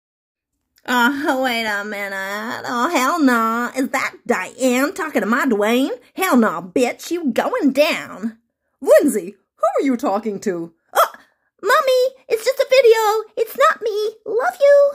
Teenage bitch / Mom US